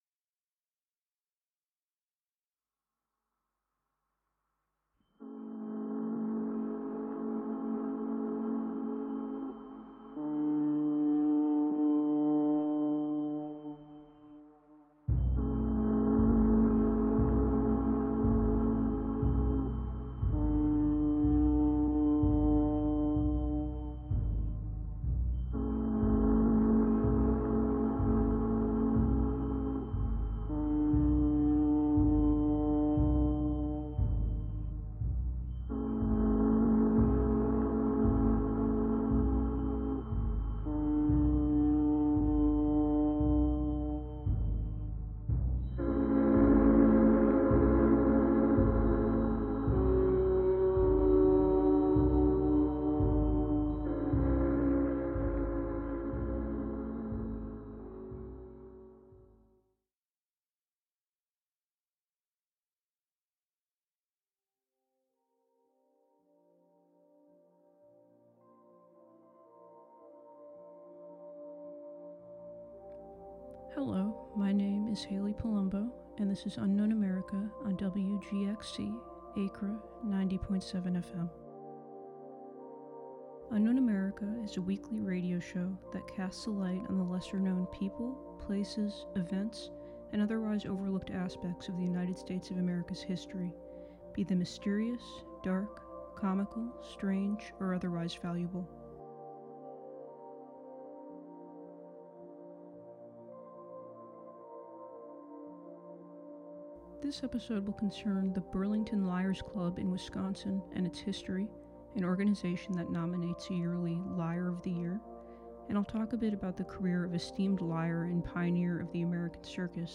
Through occasional interviews, on-site reporting, frantically obsessive research, and personal accounts, the listener will emerge out the other side just a bit wiser and more curious about the forgotten footnotes of history that make America fascinating, curious, and complicated.